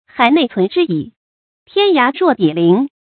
hǎi nèi cún zhī jǐ ，tiān yá ruò bǐ lín
海内存知已，天涯若比邻发音
成语正音 涯，不能读作“ái”。